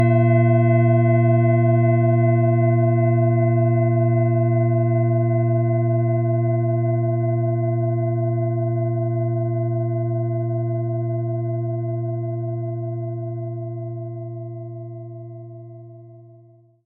Nach uralter Tradition von Hand getriebene Klangschale.
Im Audio-Player - Jetzt reinhören hören Sie genau den Original-Klang der angebotenen Schale. Wir haben versucht den Ton so authentisch wie machbar aufzunehmen, damit Sie gut wahrnehmen können, wie die Klangschale klingen wird.
Spielen Sie die Schale mit dem kostenfrei beigelegten Klöppel sanft an und sie wird wohltuend erklingen.
PlanetentonMond & Chiron (Höchster Ton)
MaterialBronze